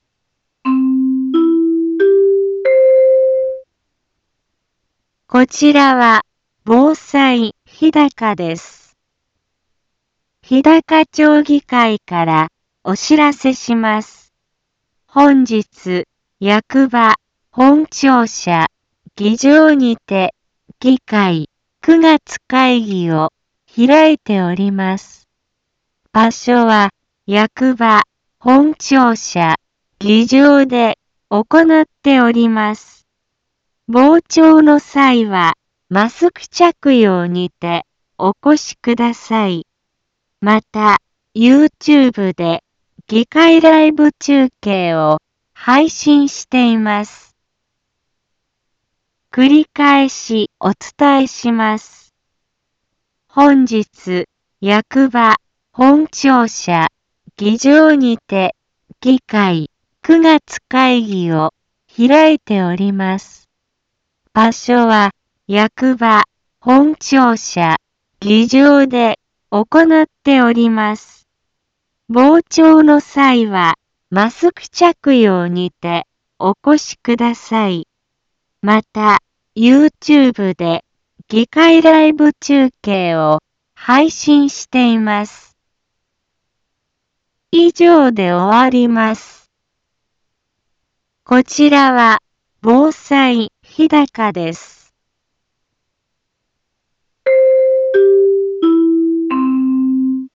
BO-SAI navi Back Home 一般放送情報 音声放送 再生 一般放送情報 登録日時：2022-09-14 10:03:32 タイトル：日高町議会９月会議のお知らせ インフォメーション：こちらは防災日高です。 本日、役場本庁舎議場にて議会９月会議を開いております。